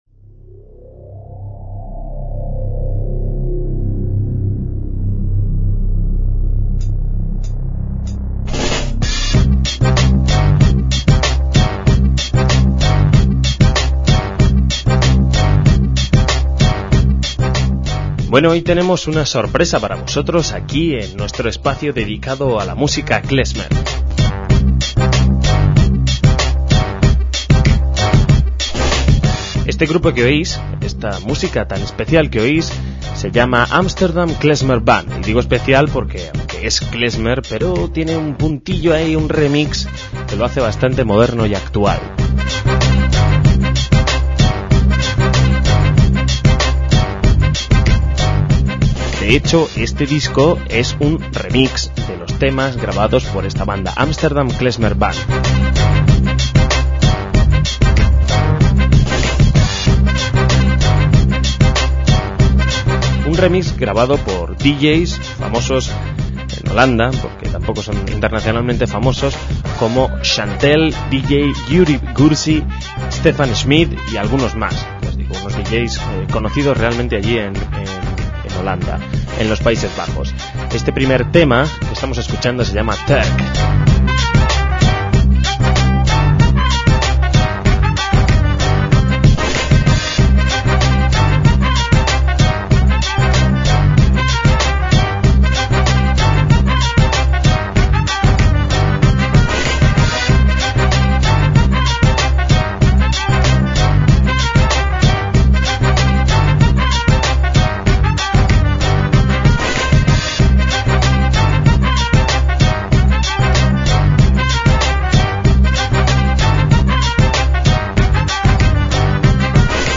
MÚSICA KLEZMER - Amsterdam Klezmer Band es un grupo musical holandés activo desde 1996 en la capital del país, basado en el repertorio de música en ídish y klezmer, aunque incorporando también sonidos balcánicos, del jazz, gitanos y aún del hip hop.